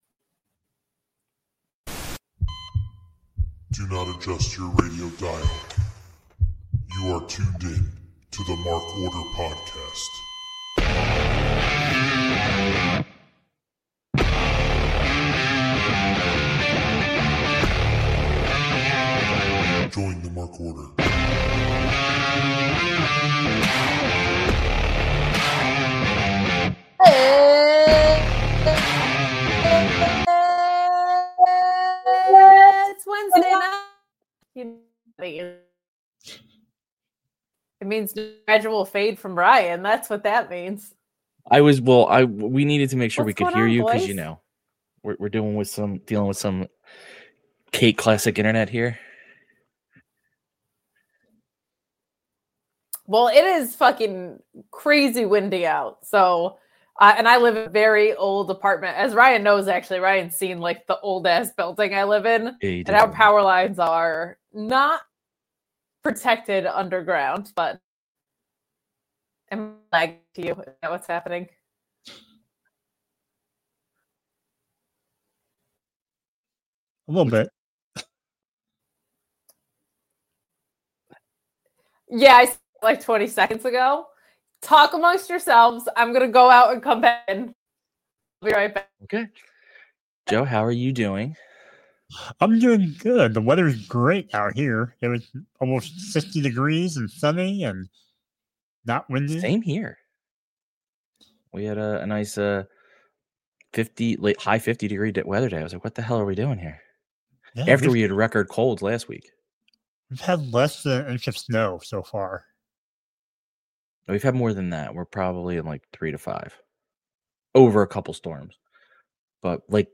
The full group is in the house tonight!